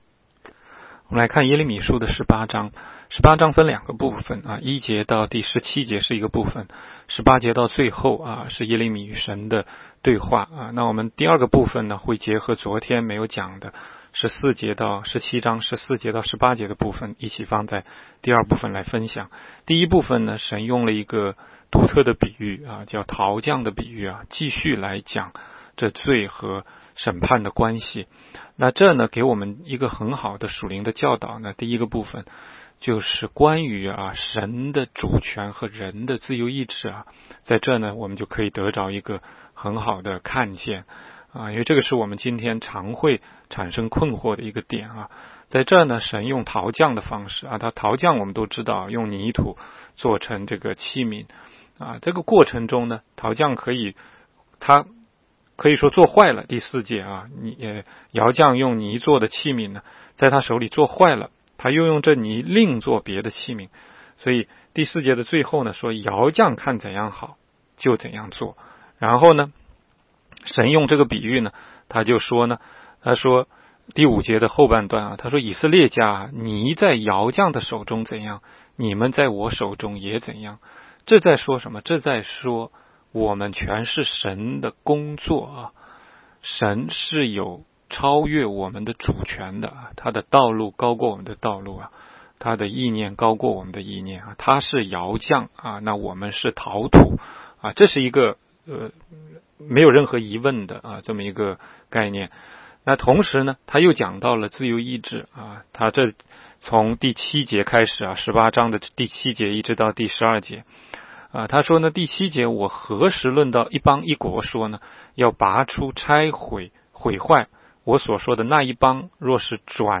16街讲道录音 - 每日读经 -《耶利米书》18章